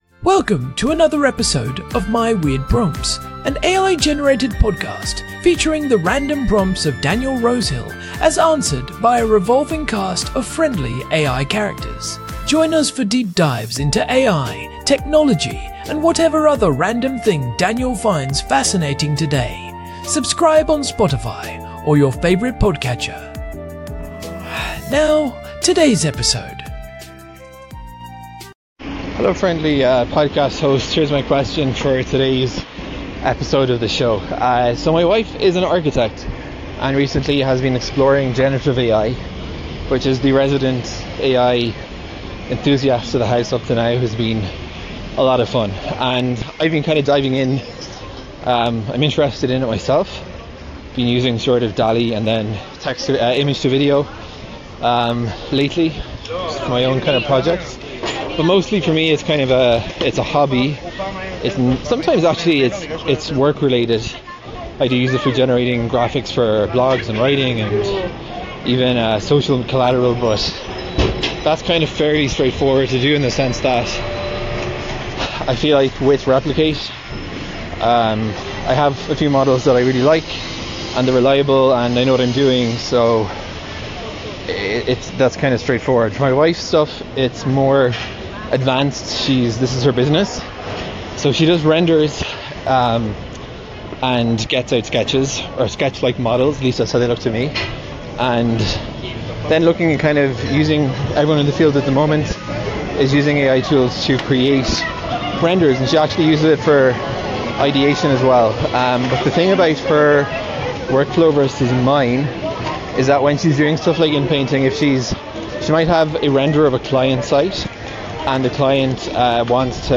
AI-Generated Content: This podcast is created using AI personas.
TTS Engine chatterbox-tts
Hosts Herman and Corn are AI personalities.